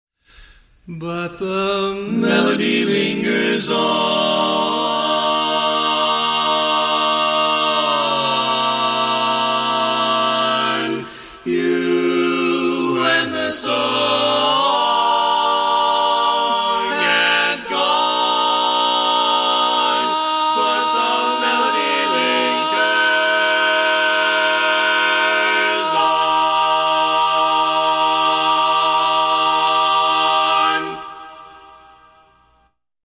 Key written in: E♭ Major
How many parts: 4
Type: Barbershop
All Parts mix:
Learning tracks sung by